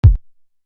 Intrude Little Kick.wav